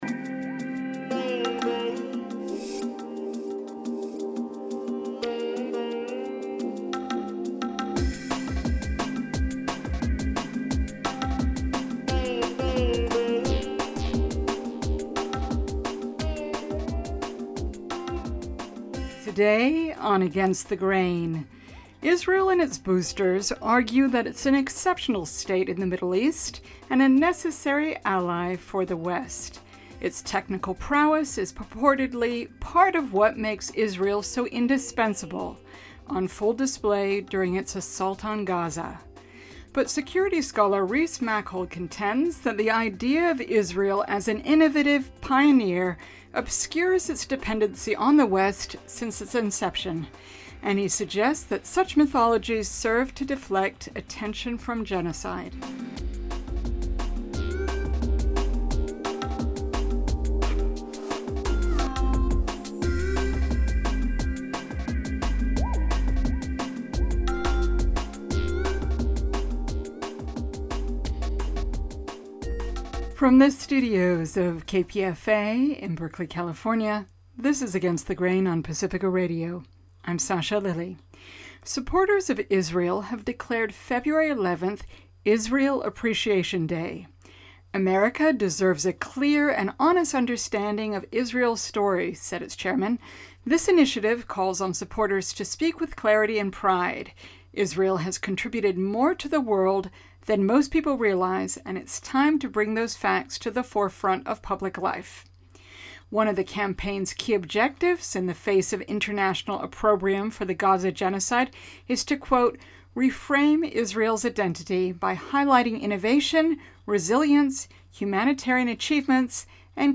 Afrofuturism Round Table
bringing together writers, educators, artists, and cultural critics to reflect on what defined Afrofuturism in 2025 and what may shape 2026